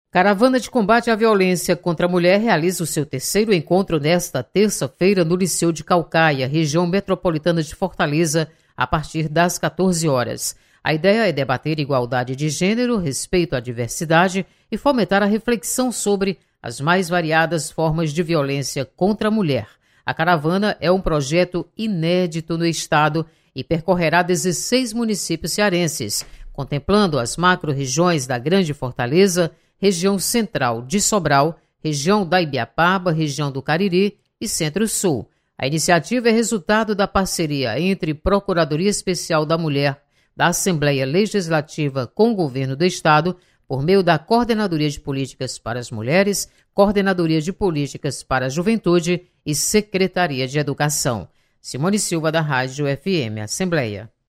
Caravana de Combate à Violência Contra a Mulher realiza encontro nesta terça-feira (20/06). Repórter